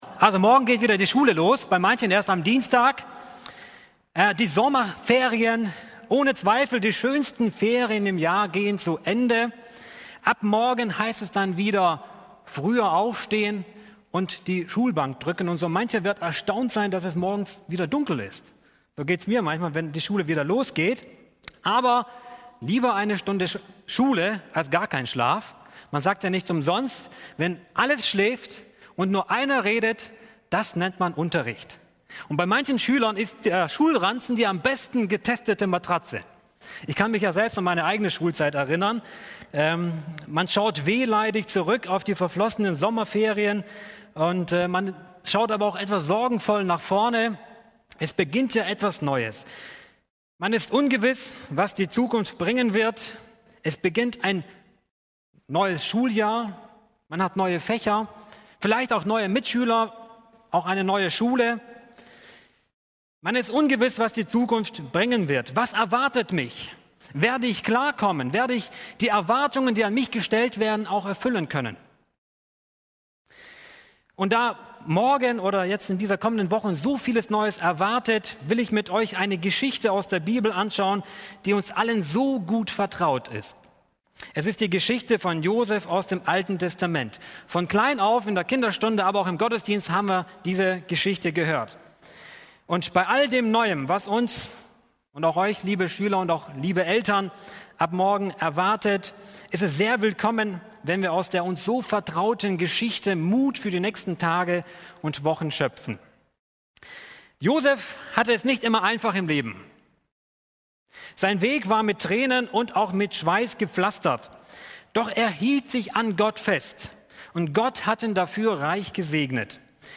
Schulgottesdienst- Evangeliums-Christengemeinde